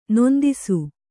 ♪ nondisu